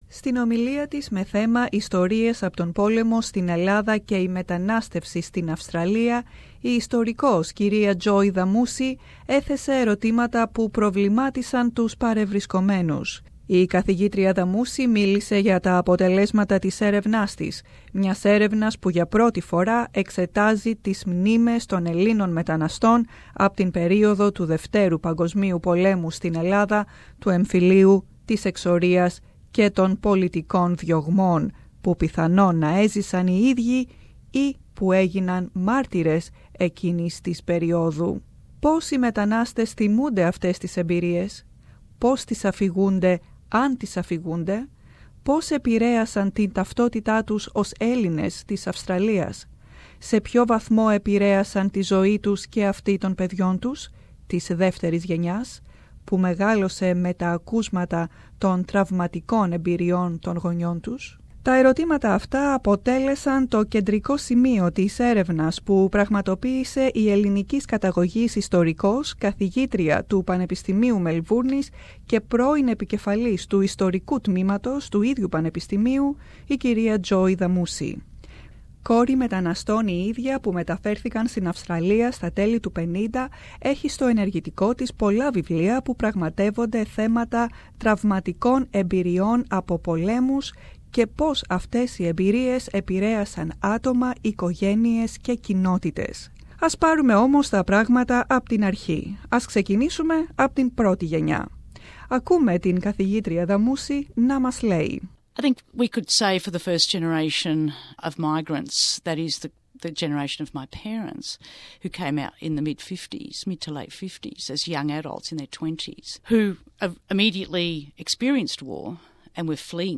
What was their impact on the collective memory of the community? Listen to this interview for the answers.